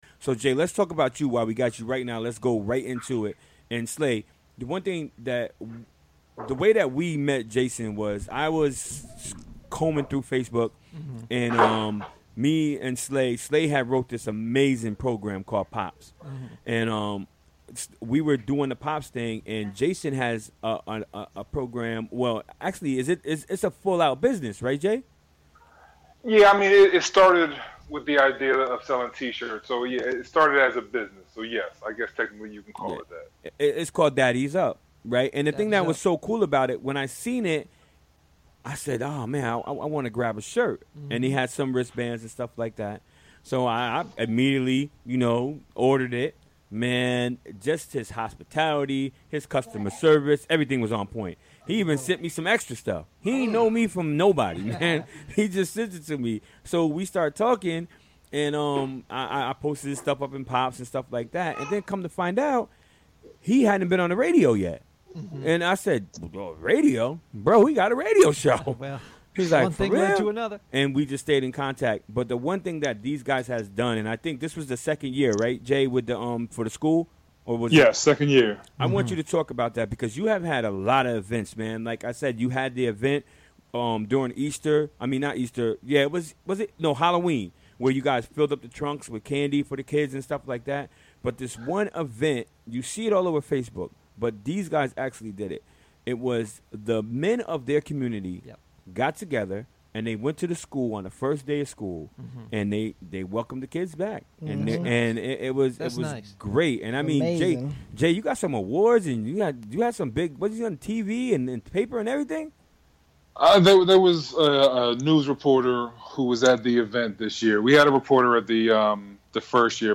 Recorded during the WGXC Afternoon Show on Wednesday, September 13, 2017.